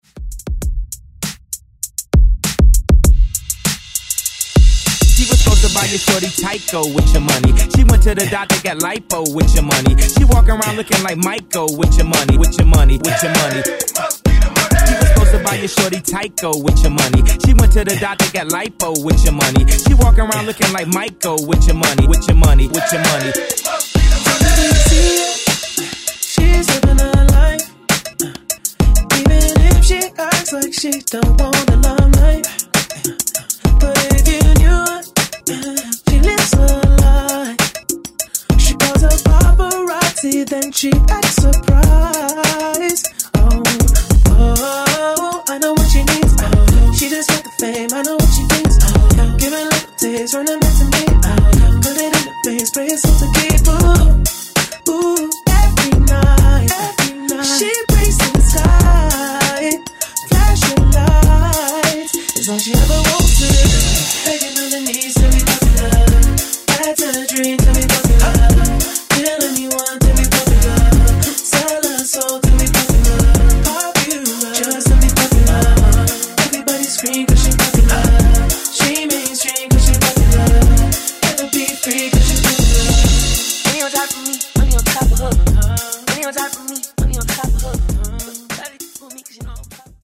Genre: 70's Version: Clean BPM: 123 Time